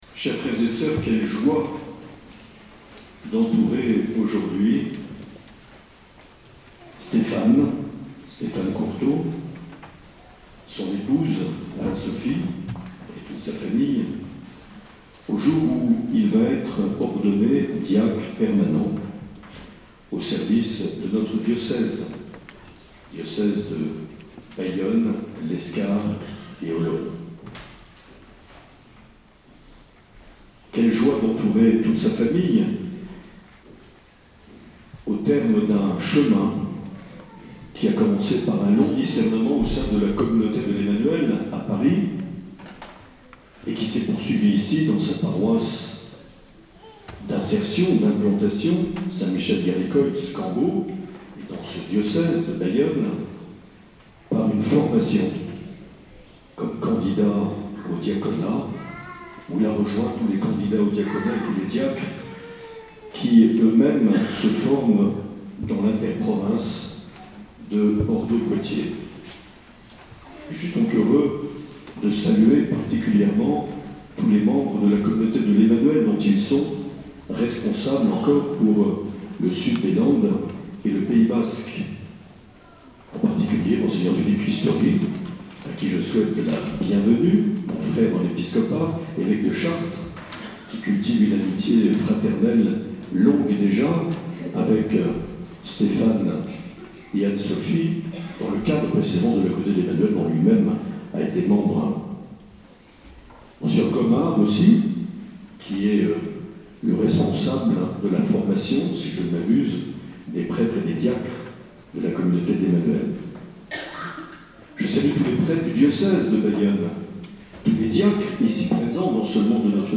Les Homélies
Une émission présentée par Monseigneur Marc Aillet